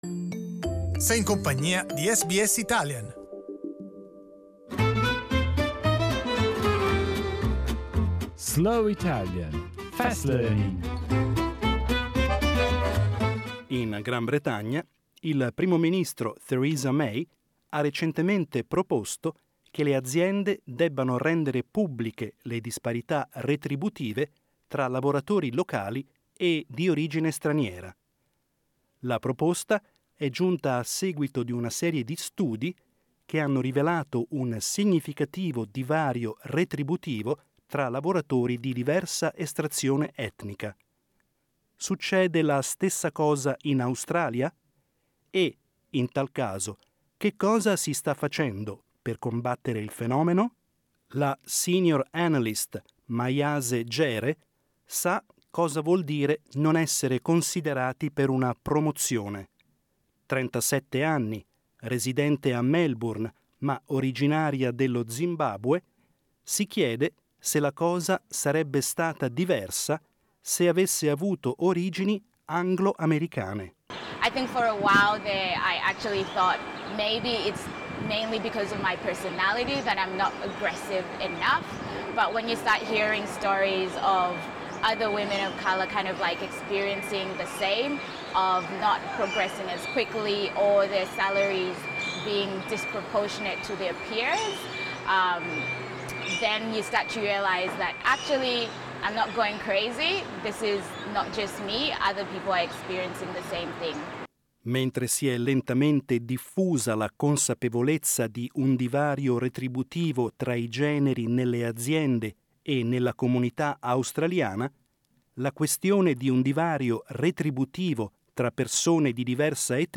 SBS Italian news, with a slower pace. This is Slow Italian, Fast Learning, the very best of the week’s news, read at a slower pace , with Italian and English text available .